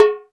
Hip House(57).wav